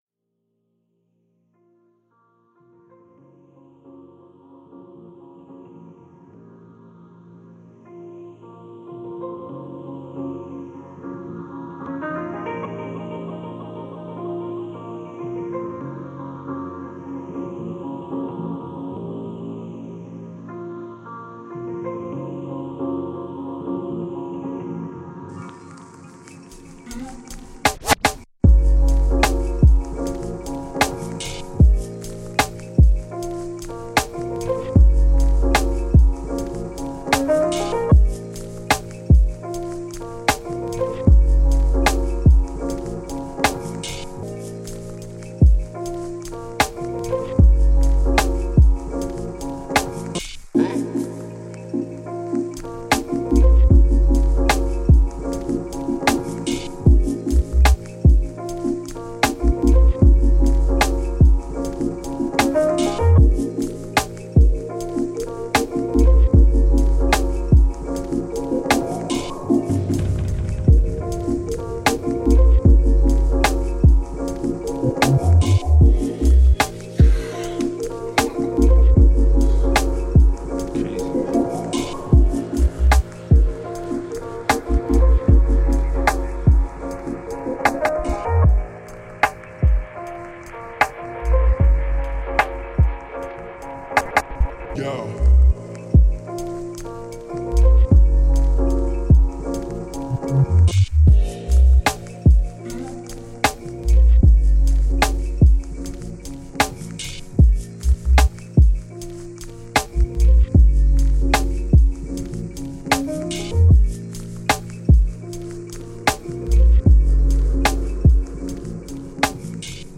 Ambiance Étude : Lecture Longue